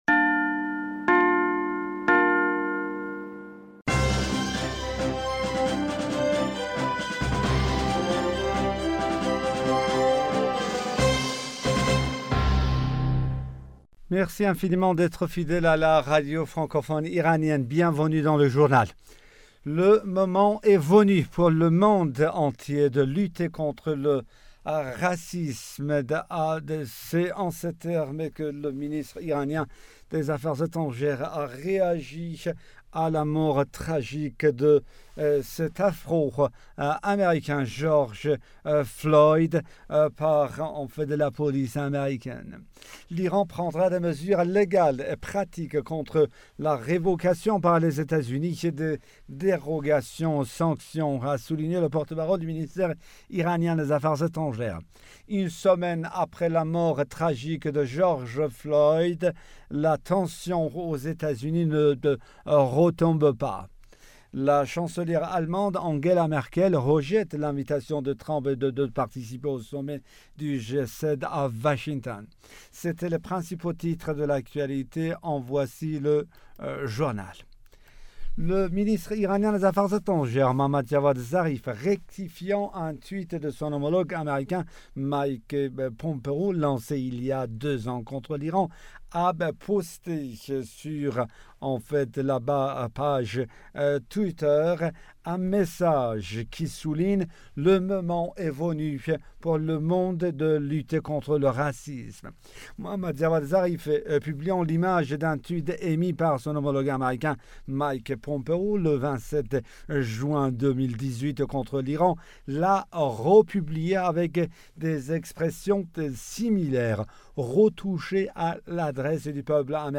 Bulletin d'information du 31 mai 2020